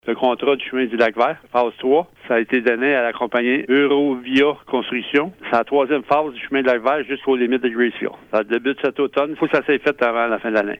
Gary Lachapelle, maire de Lac-Sainte-Marie, confirme que sa municipalité a octroyé un contrat à l’entreprise Eurovia Construction Inc. pour mettre à terme ce projet. Le voici qui offre plus de détails :